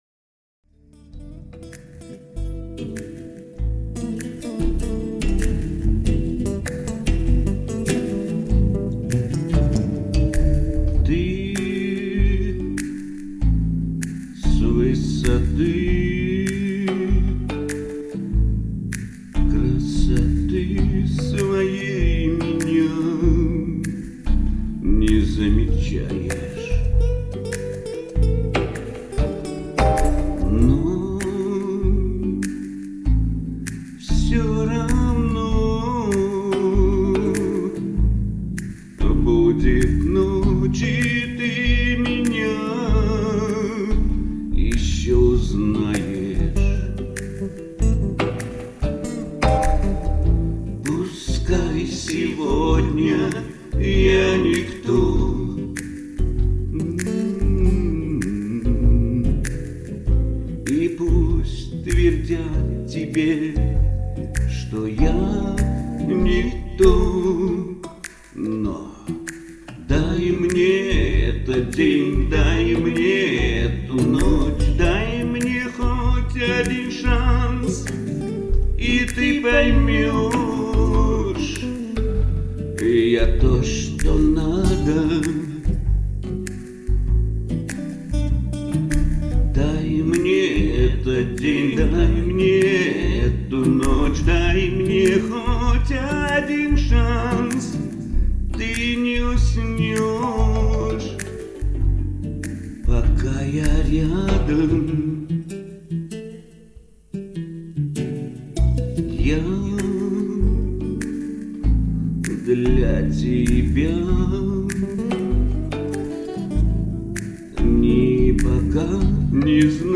импровизировал с каким то восточным акцентом, но не плохо.